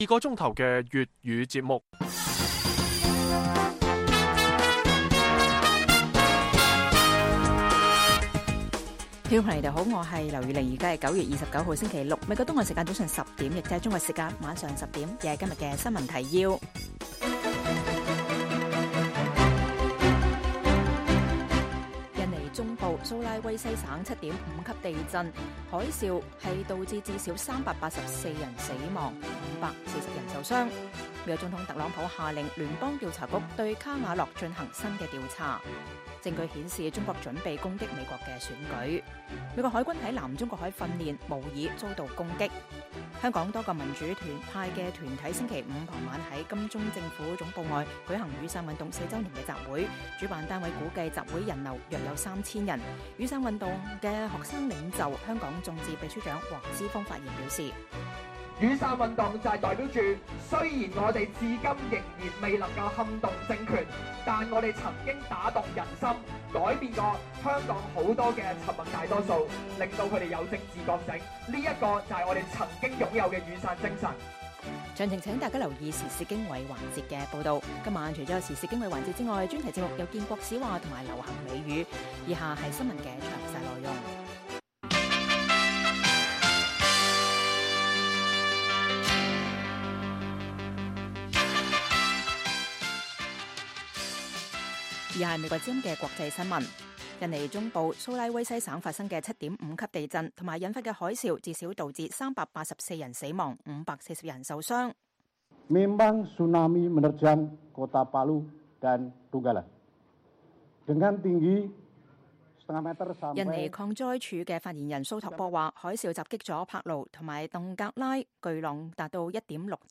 粵語新聞 晚上10-11點
北京時間每晚10－11點 (1400-1500 UTC)粵語廣播節目。內容包括國際新聞、時事經緯和英語教學。